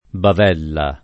bavella [ bav $ lla ] s. f.